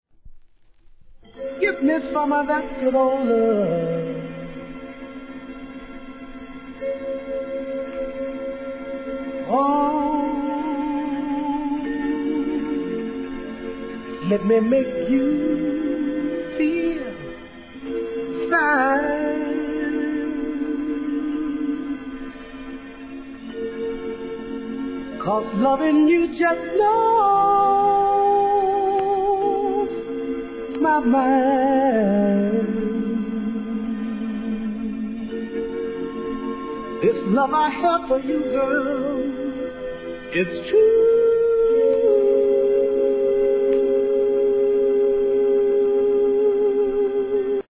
1. SOUL/FUNK/etc...